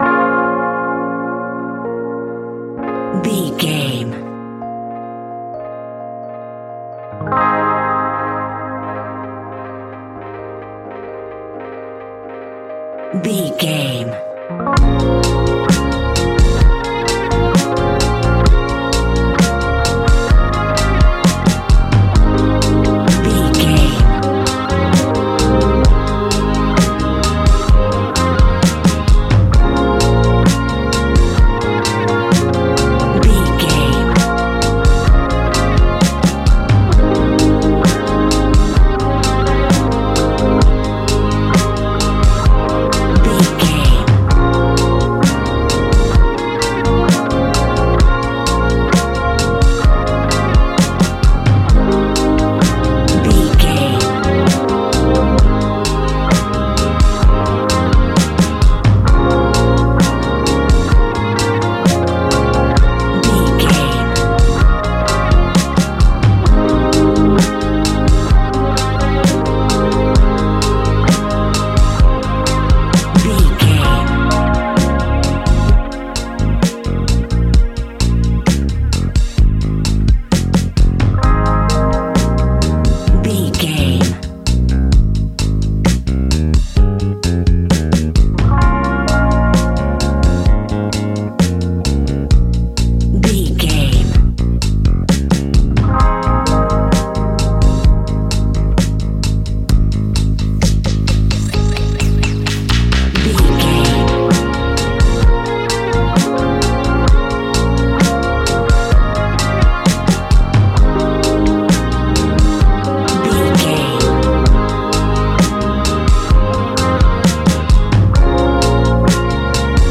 Ionian/Major
G♯
laid back
Lounge
sparse
new age
chilled electronica
ambient
atmospheric